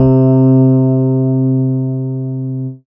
Rhodes_C2.wav